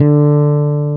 Index of /90_sSampleCDs/East Collexion - Bass S3000/Partition A/FRETLESS-E